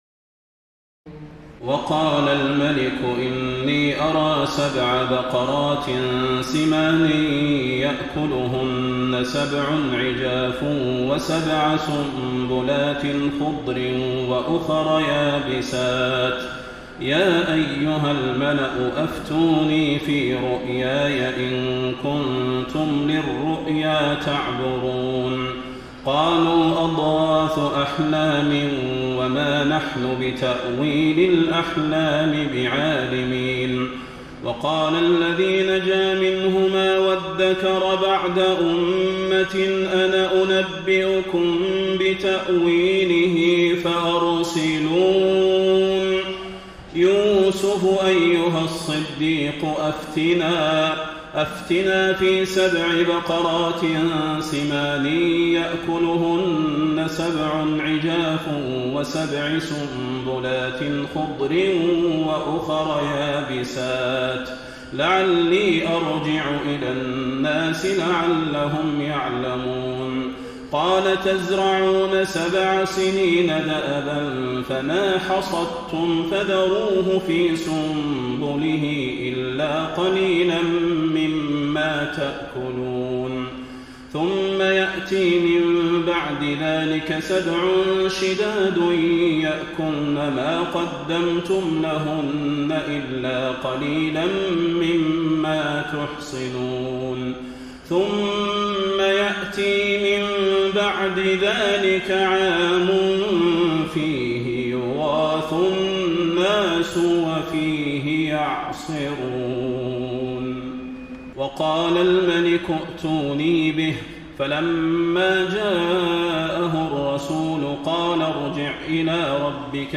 تراويح الليلة الثانية عشر رمضان 1433هـ من سورتي يوسف (43-111) و الرعد (1-18) Taraweeh 12 st night Ramadan 1433H from Surah Yusuf and Ar-Ra'd > تراويح الحرم النبوي عام 1433 🕌 > التراويح - تلاوات الحرمين